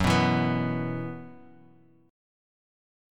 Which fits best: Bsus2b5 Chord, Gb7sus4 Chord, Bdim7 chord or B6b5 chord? Gb7sus4 Chord